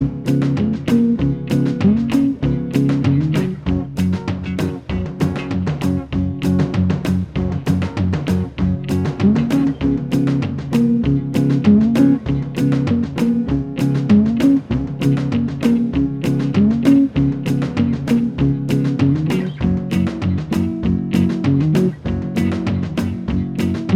Minus Lead Guitar Rock 'n' Roll 2:19 Buy £1.50